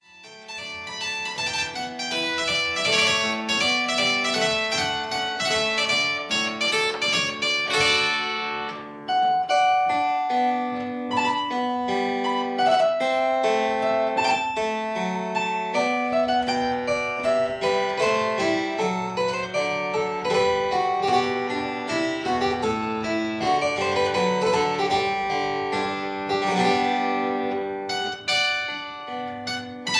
two manual harpsichord